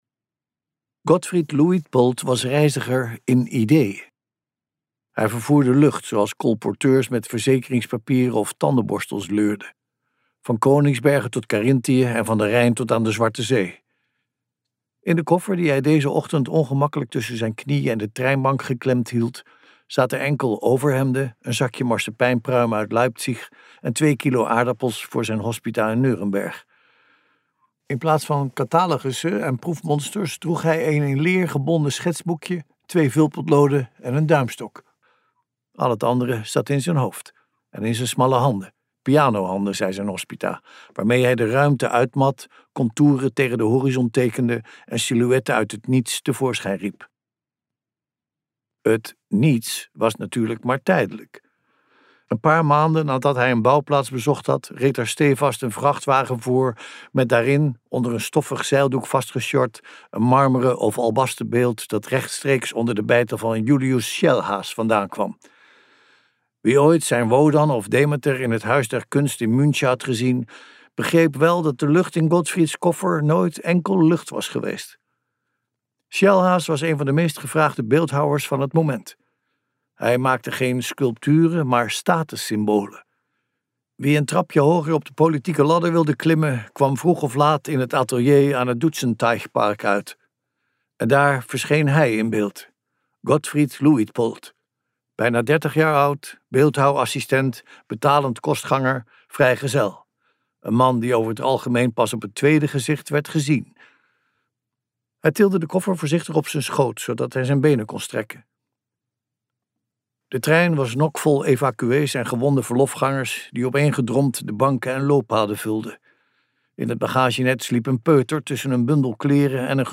KokBoekencentrum | Een tijd als deze luisterboek